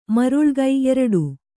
♪ maruḷgai